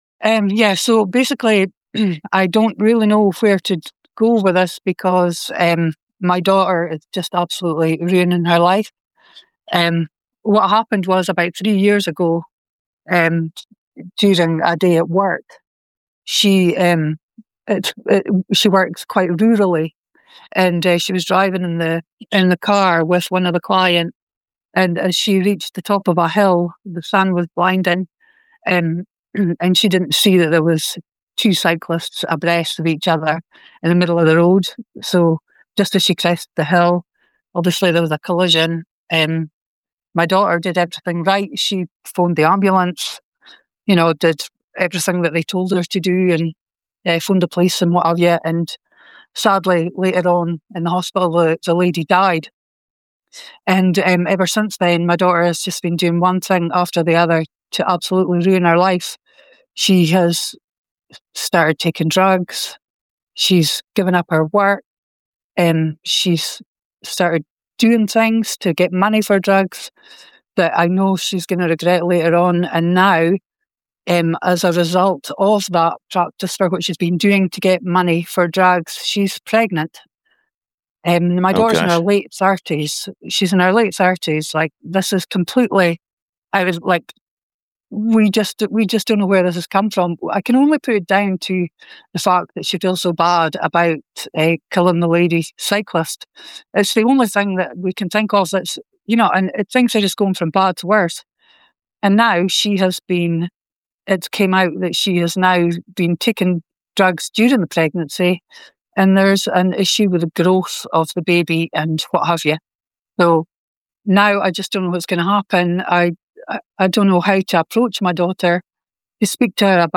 CALL IN SHOW